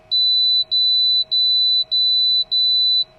As of firmware version 8.0.1, all units now play various sound sequences with ZTD deployments.
In case a failure occurs (e.g., ZTD is reverted), the box will play the following sound: